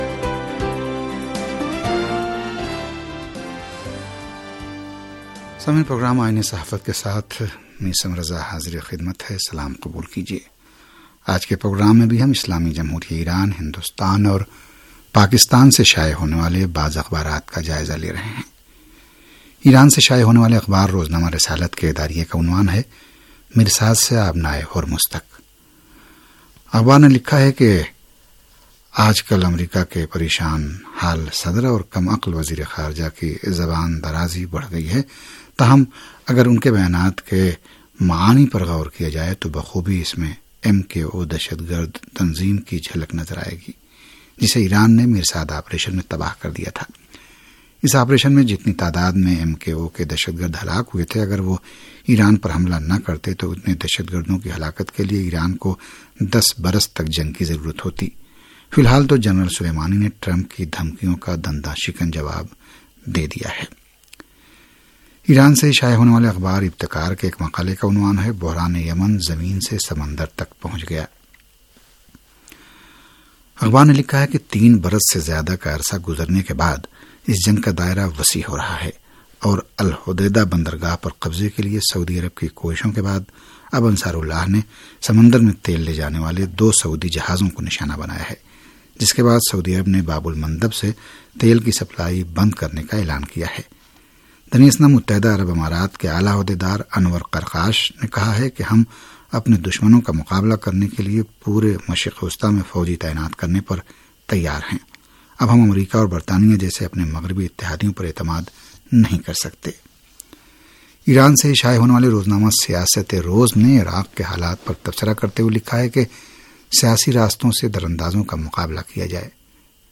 ریڈیو تہران کا اخبارات کے جائزے پر مبنی پروگرام